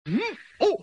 Finally, an exclamation of pain and two of